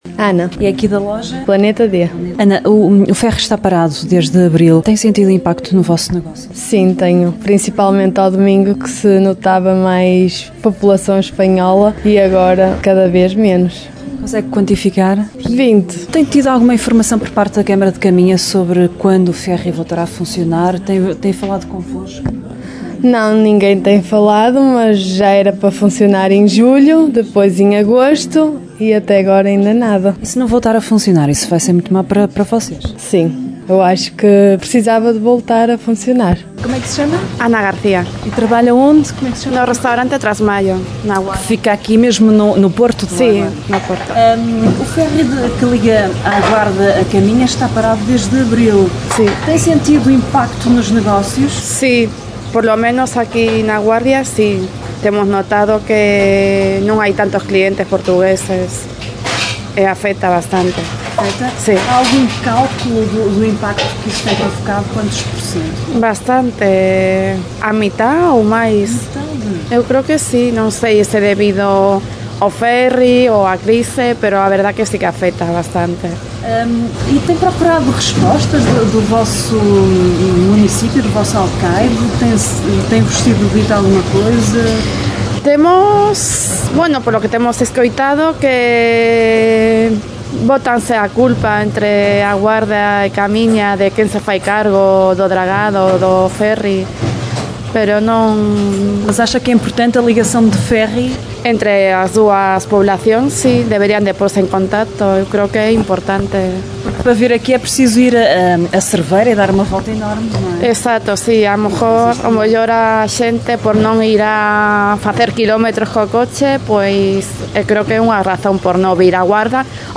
A Rádio Caminha saiu à rua e foi ouvir os protestos, que esta semana está a emitir.